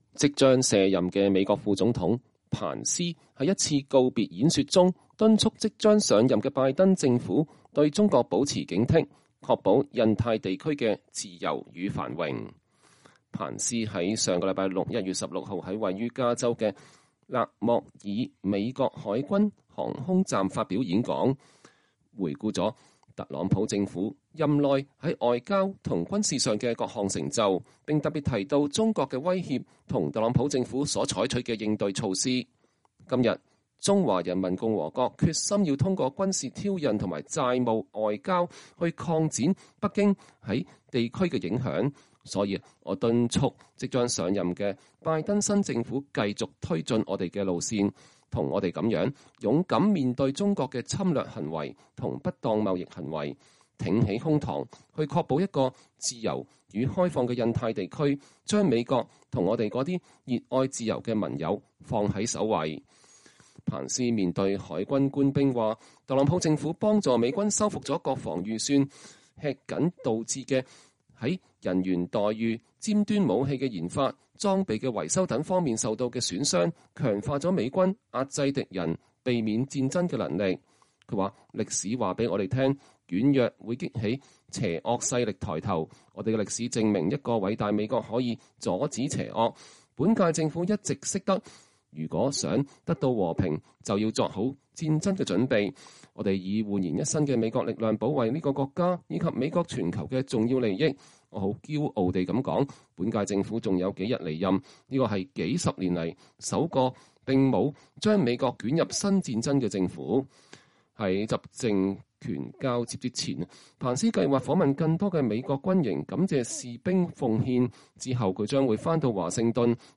彭斯發表告別演說 促拜登政府上任後保持對中國的警惕
彭斯星期六（2021年1月16日）在位於加州的勒莫爾美國海軍航空站發表演說，回顧了特朗普政府任內在外交和軍事上的各項成就，並特別提到了中國的威脅和特朗普政府採取的應對措施。